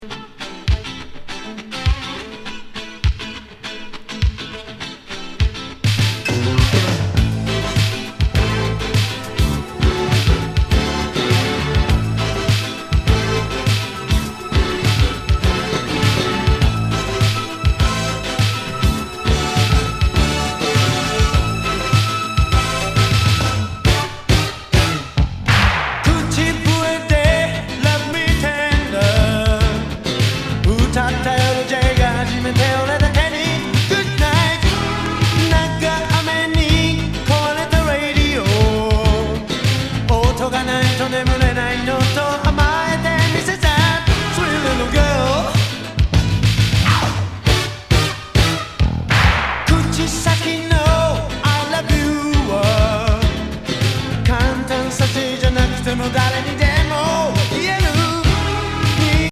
シンセ・アーバン・ファンカー！